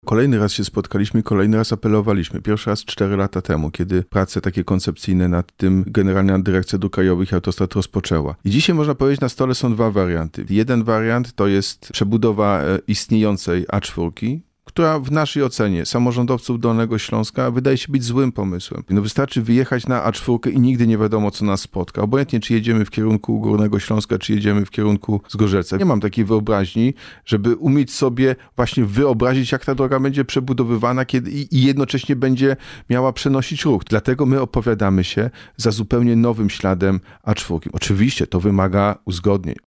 W audycji „Poranny Gość” pytaliśmy Jacka Sutryka również o wariant autostrady A4, który decyzją GDDKiA może ominąć Wrocław. -Jesteśmy za wariantem utworzenia drogi w nowym śladzie. – mówi prezydent.